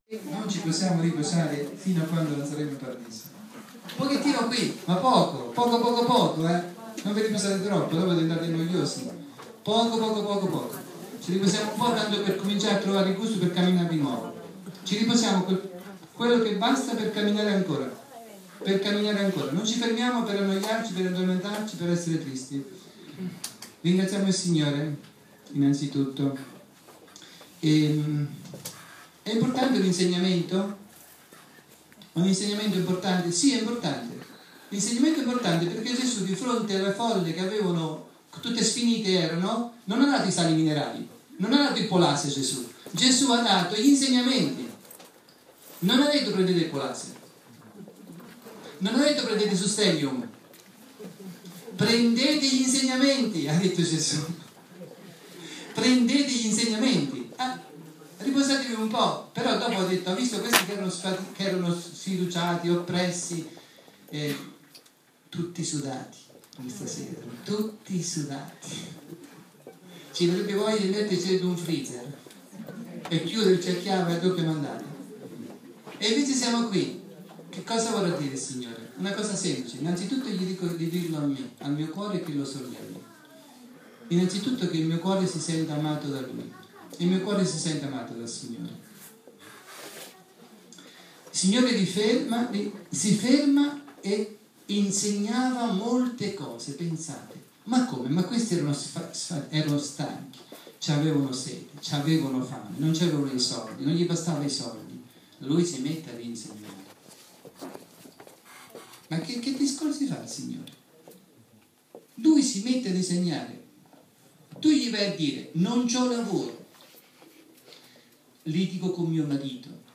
clic col tasto destro per scaricare il file Catechesi 21 luglio 2015